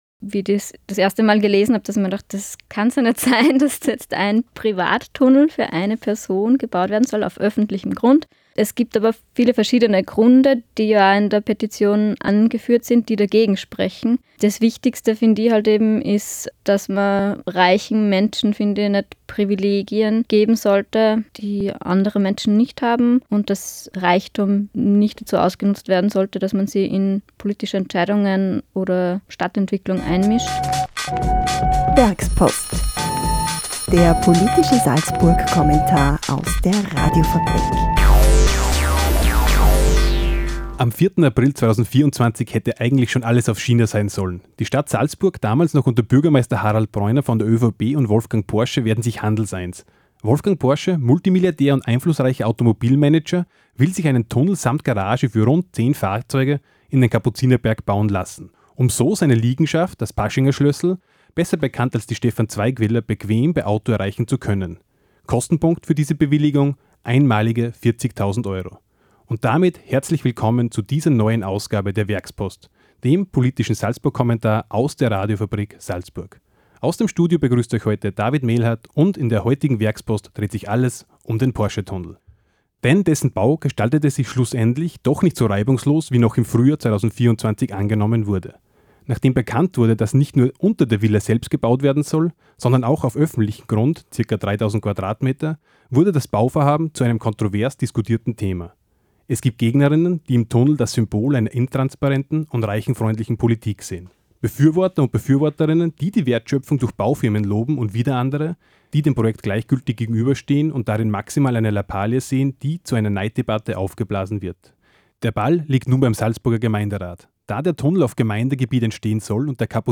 Der Werkspodcast vertieft das jeweilige Thema des Werkspost-Kommentars in einem Studiogespräch.